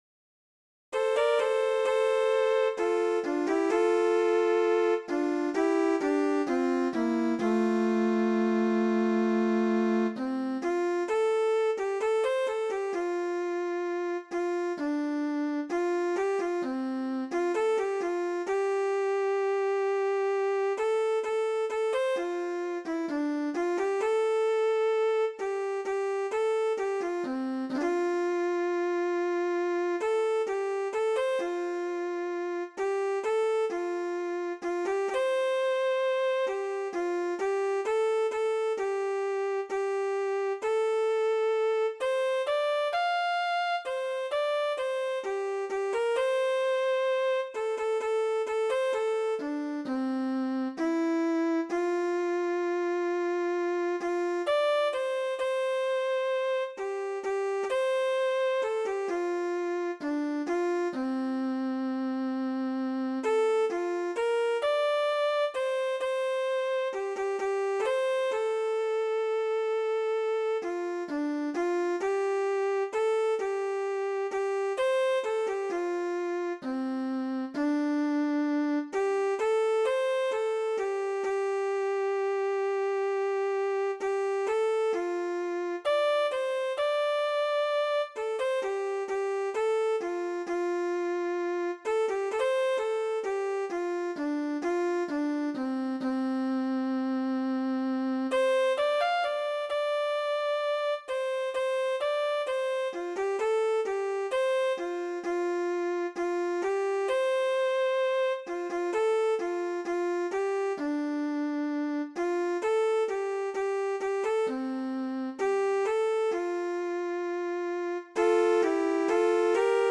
Thánh Ca Phụng Vụ